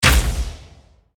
archer_skill_backjumpshot_shot_a.ogg